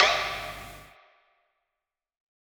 Percs
SOUTHSIDE_percussion_pitchy.wav